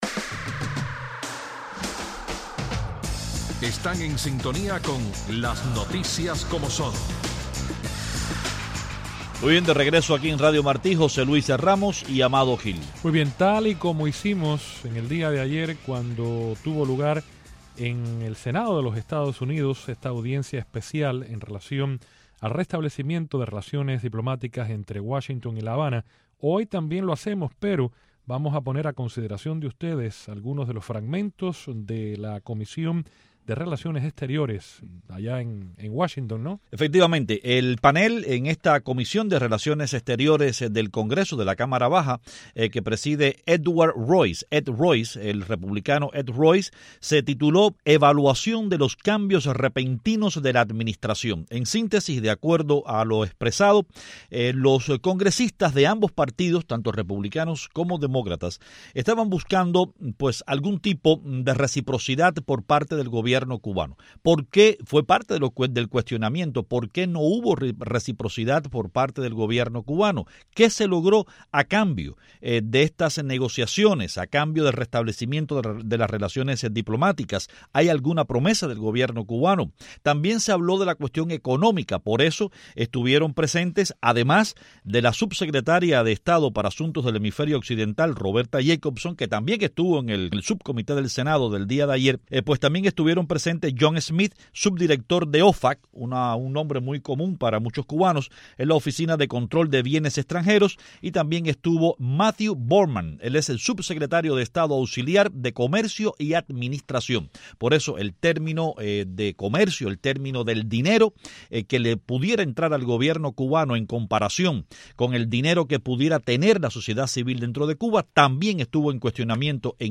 Ofrecemos fragmentos de la audiencia celebrado por el Comité de Asuntos Internacionales de la Cámara de Representantes sobre la nueva política hacia Cuba de EEUU.